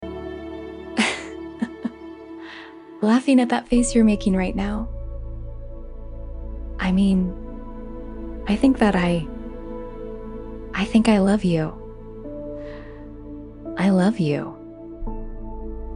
点开这个播放超过6万的视频，轻柔的笑声、停顿、细微的呼吸、郑重的告白让人简直难以相信这是完全由AI合成的
甚至连最后表露身份的自白，都能让人听出一种忧伤的情绪：
因为我的声音并非来自一个真实的人，而是全部由计算机生成的。